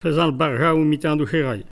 Mémoires et Patrimoines vivants - RaddO est une base de données d'archives iconographiques et sonores.
Langue Maraîchin
Catégorie Locution